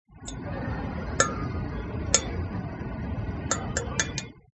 描述：este audio hace parte del foley de“the Elephant's dream”
标签： 步行 金属 步骤
声道立体声